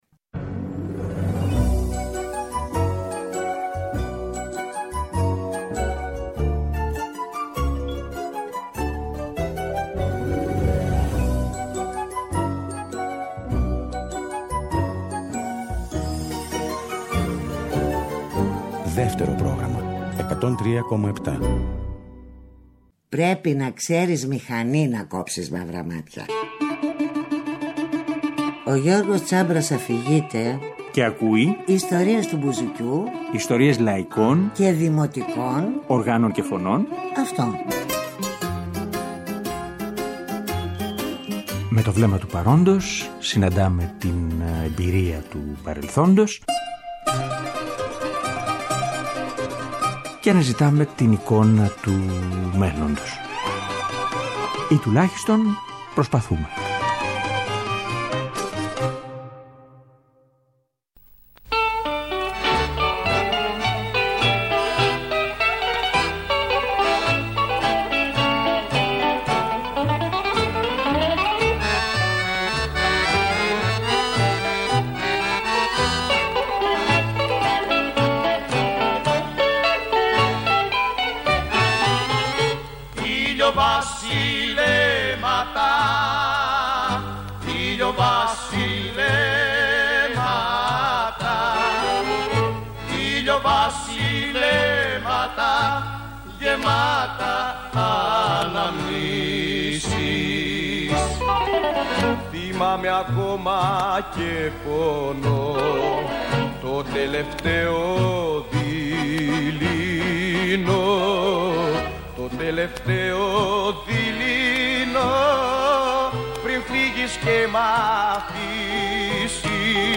μπουζούκι